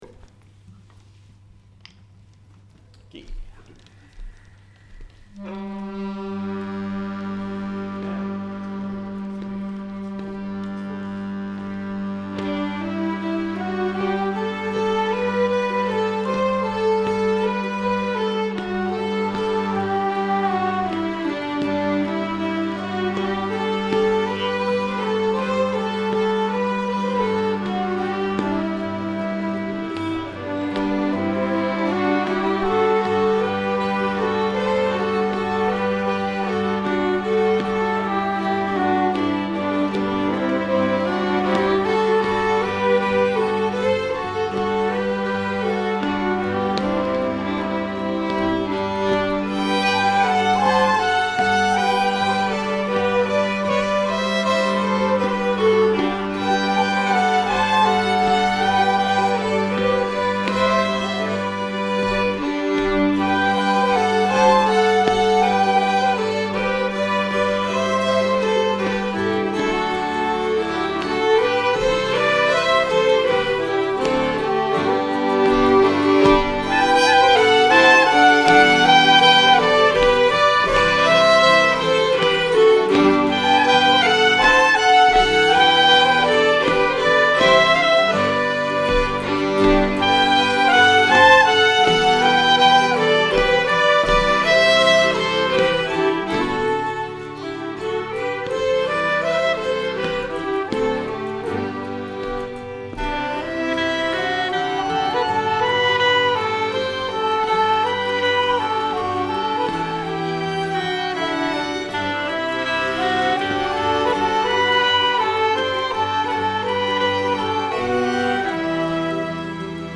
Arrangemang till studentspelmanslags-VM 2021.
Vi spelar Pellpärsvalsen av Jonas Olsson och Slängpolska efter Rosenberg.